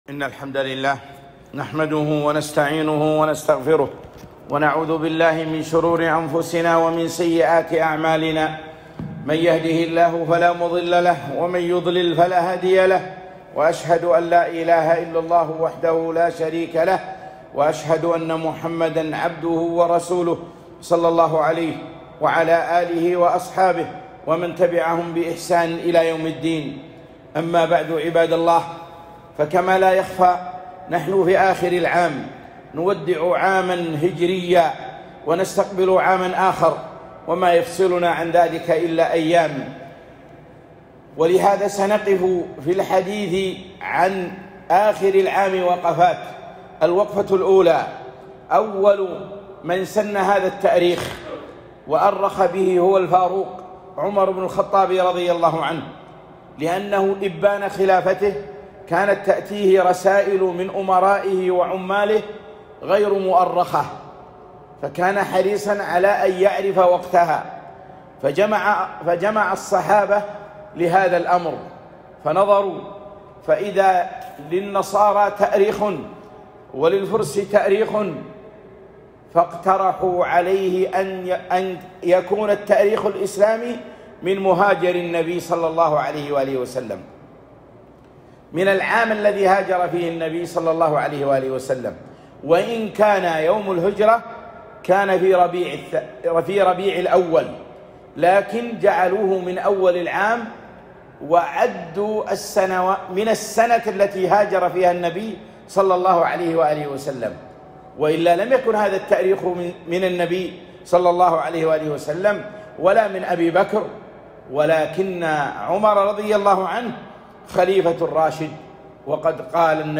خطبة - مخالفات آخر العام